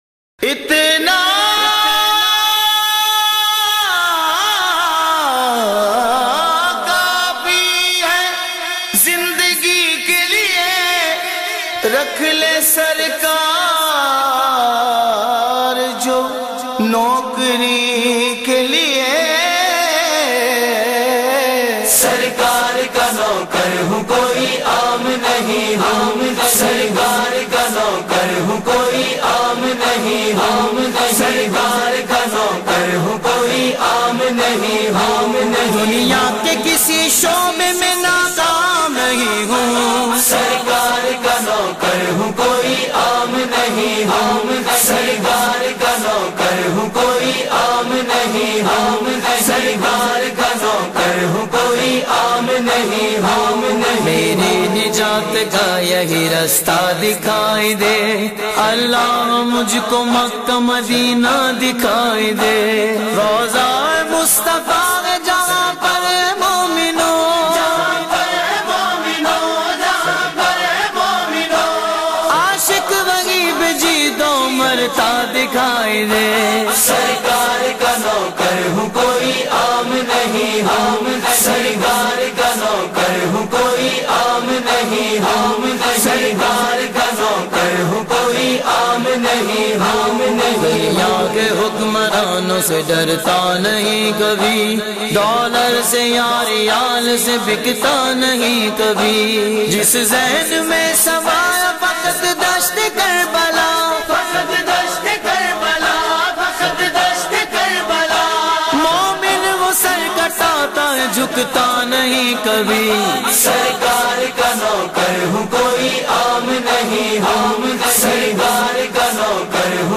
soulful and heartwarming naat recitations.